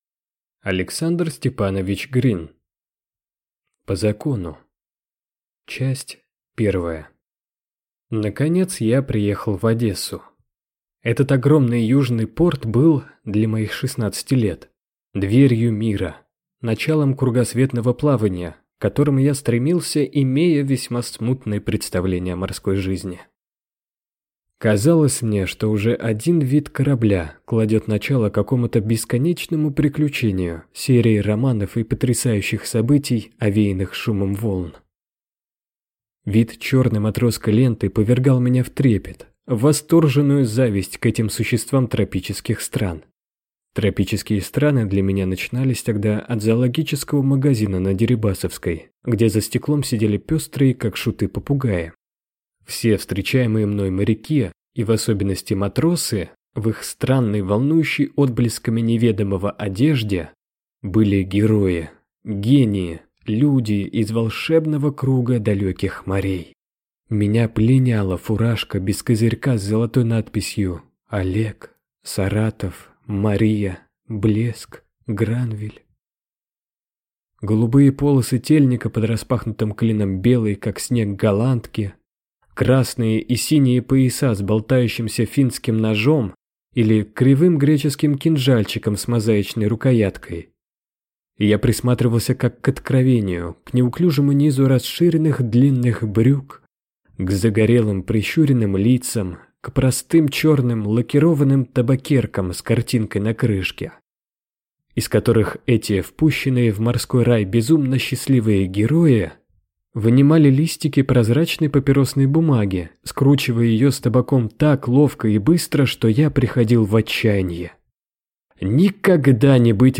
Аудиокнига По закону | Библиотека аудиокниг